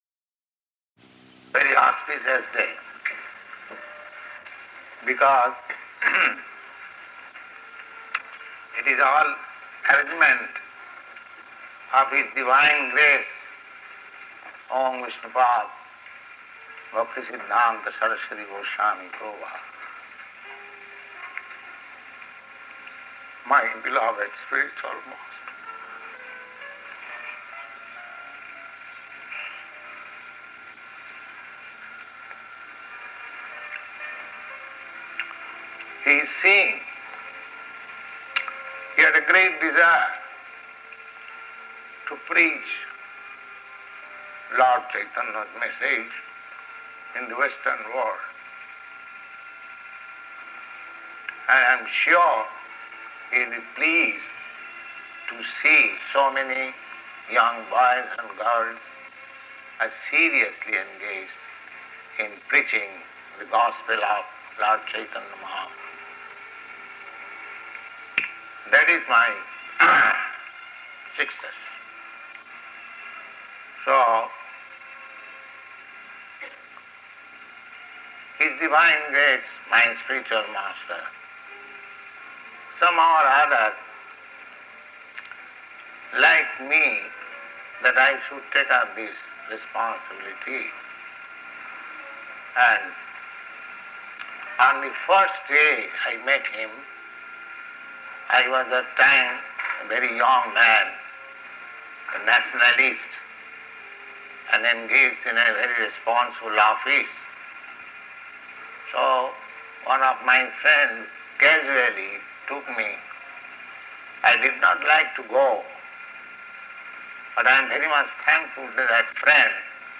[Prabhupāda's voice chokes up]
Type: Initiation
Location: Montreal